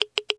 geiger_2.ogg